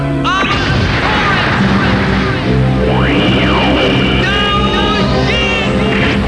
- Sai`s powerup
saicry.wav